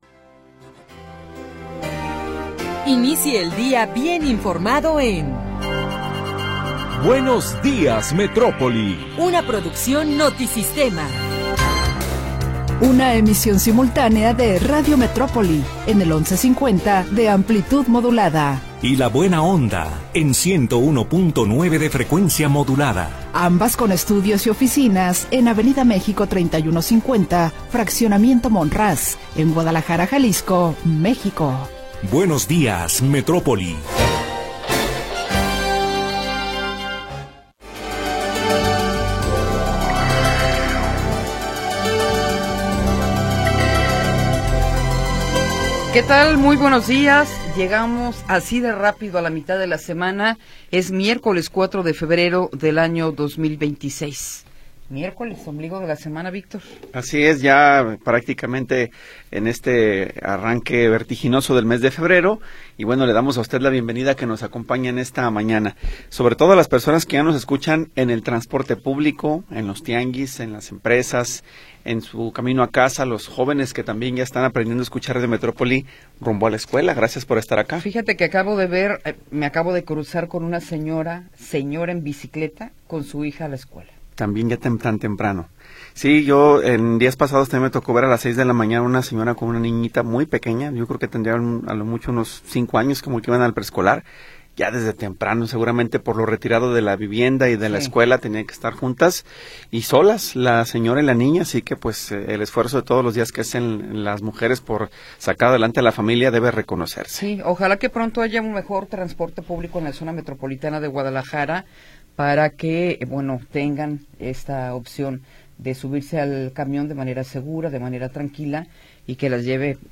Primera hora del programa transmitido el 4 de Febrero de 2026.